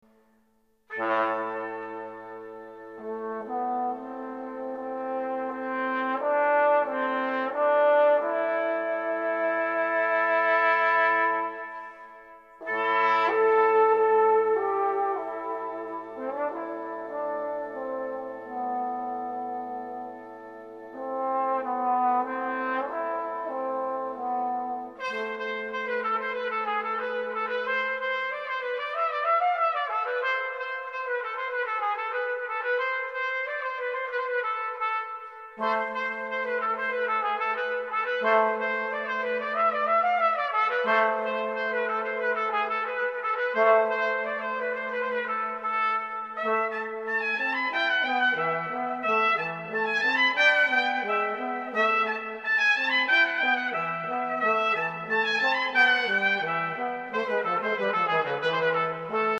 BRASS QUARTETS
1. Picc trpt 2. Trpt 3. Trb 4. Bass-trb /tuba
Music inspired of Swedish folk music